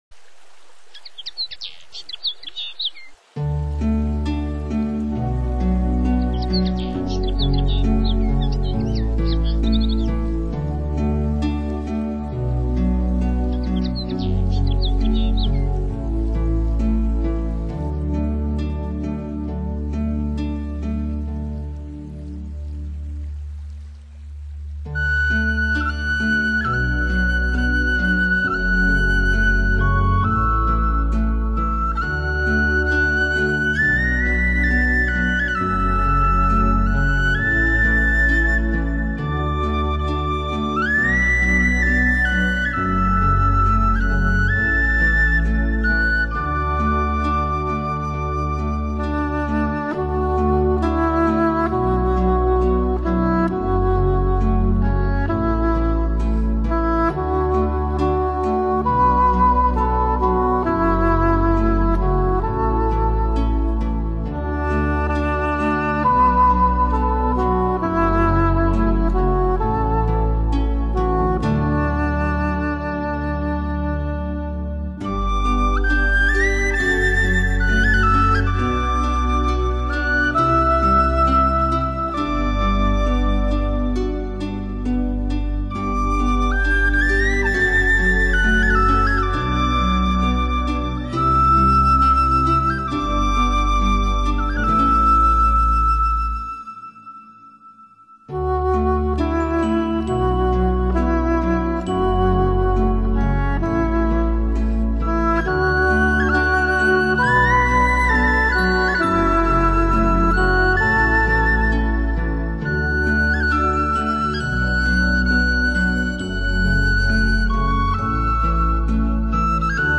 专辑语言：纯音乐
淡淡的音乐，自然的氛围